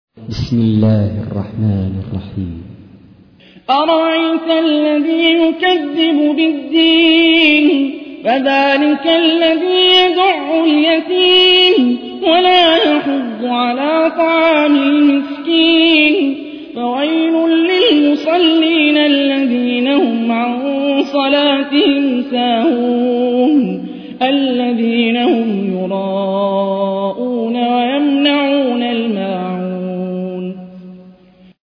تحميل : 107. سورة الماعون / القارئ هاني الرفاعي / القرآن الكريم / موقع يا حسين